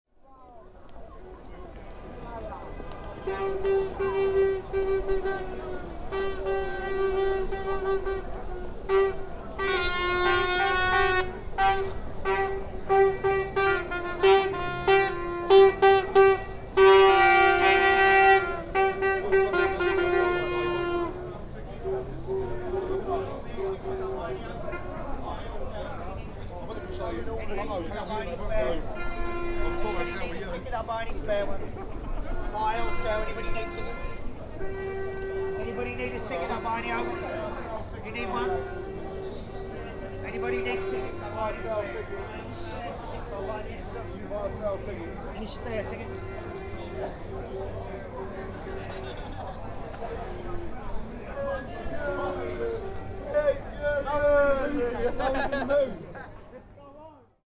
Wembley touts
Tags: London UK Beggar sounds Hustler sounds Hustlers and beggars Sounds